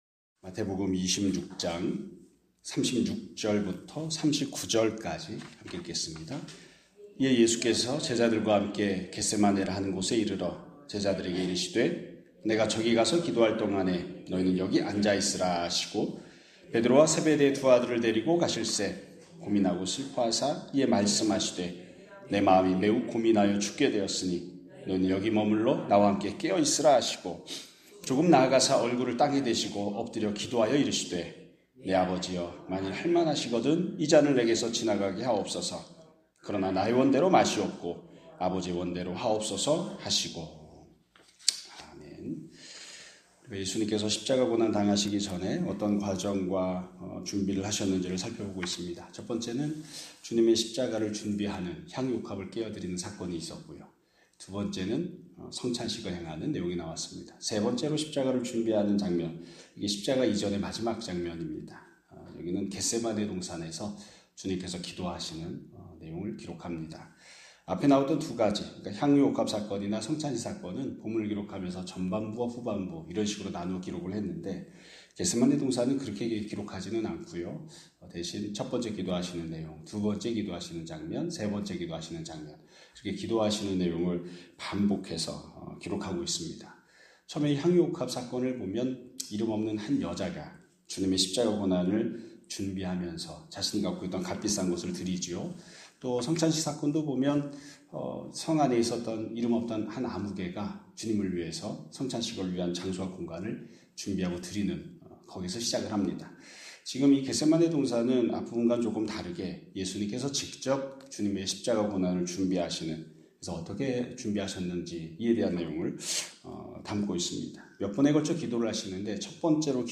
2026년 4월 3일 (금요일) <아침예배> 설교입니다.